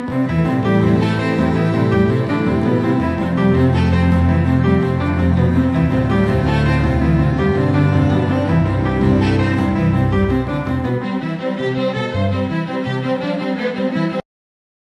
Voici un petit générique météo fictif.